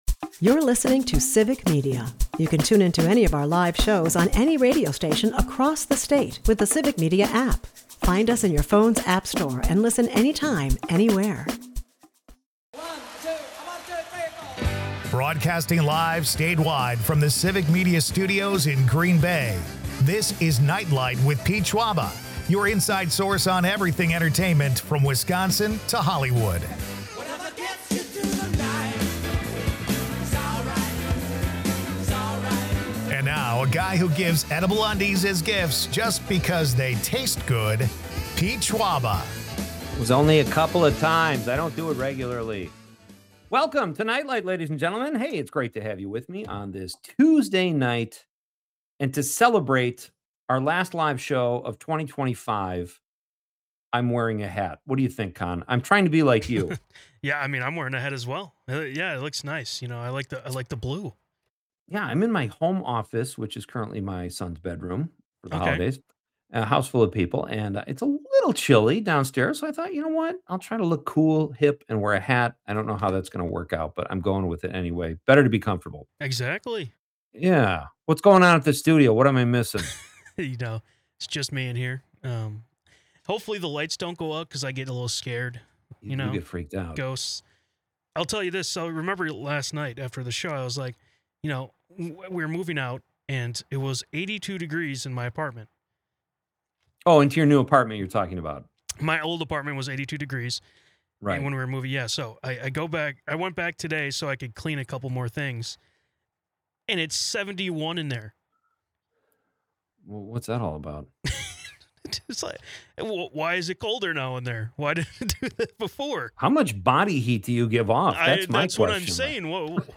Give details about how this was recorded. Special guests discuss Wisconsin's entertainment scene, including Oshkosh's Celebration of Lights and New Year's plans in Milwaukee and Madison. Nite Lite's final 2025 live show promises humor, Packers insights, and upcoming comedy events, ensuring listeners end the year with a smile.